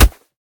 kick1.ogg